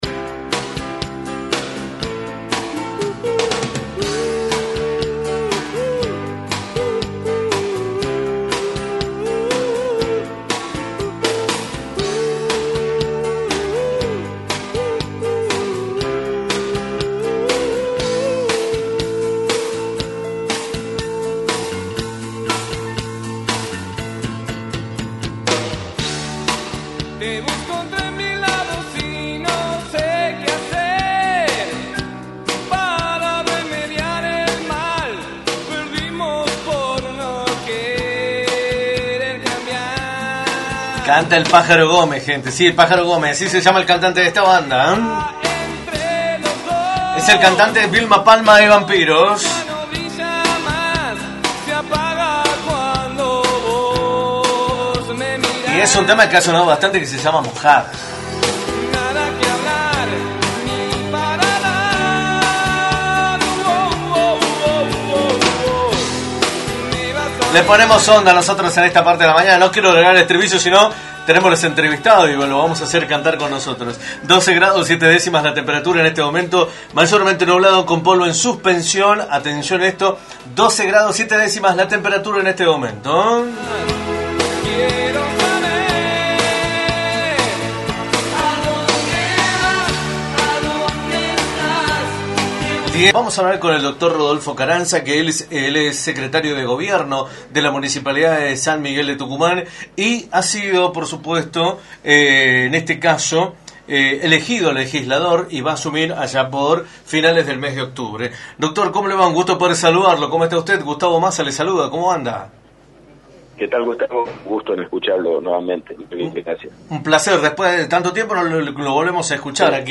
Rodolfo Ocaranza, Secretario de Gobierno de la Municipalidad de San Miguel de Tucumán y Legislador electo, analizó en Radio del Plata Tucumán, por la 93.9, la situación social y política de la provincia y del país.
entrevista